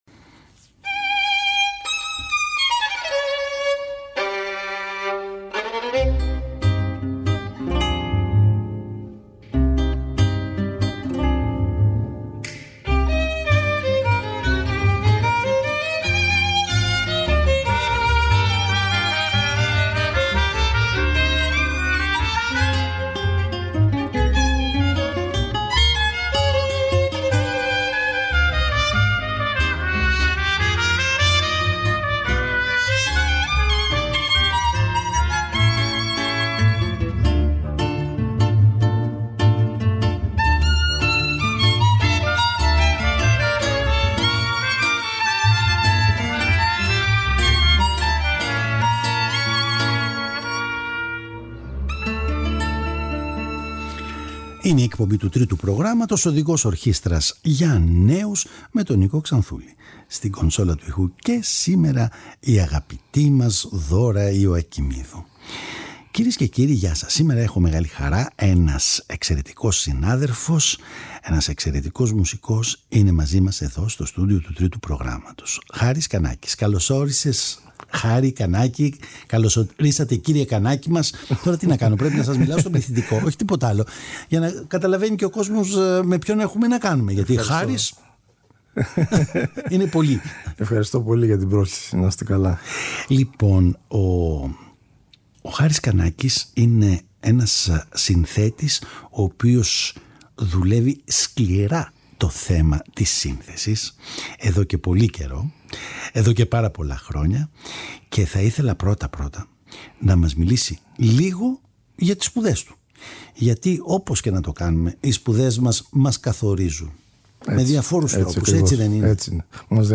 Μια συνέντευξη εφ όλης της ύλης.